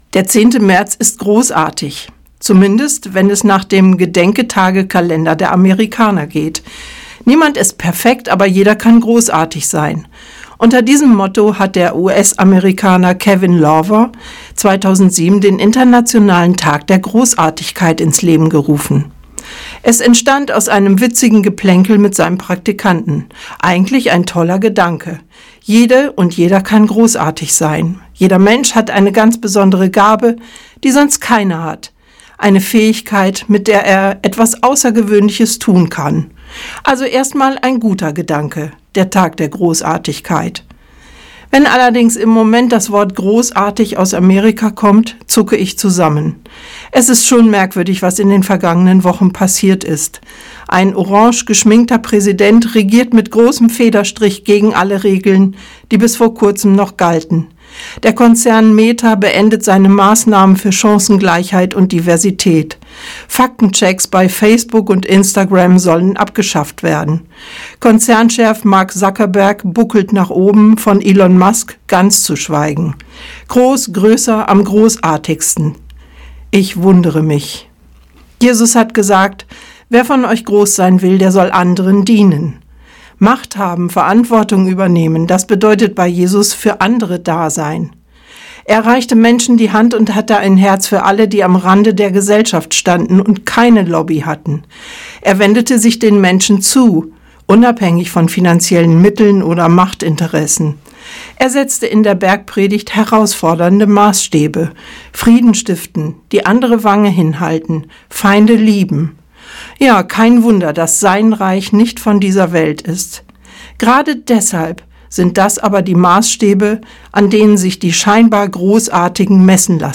Radioandacht vom 10. Februar